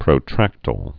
(prō-trăktəl, -tīl, prə-) also pro·tract·i·ble (-tə-bəl)